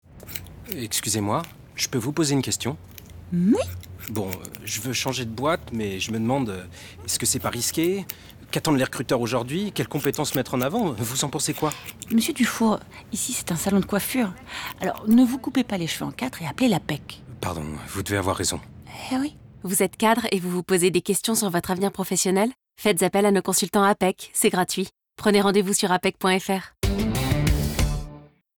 Démo voix 3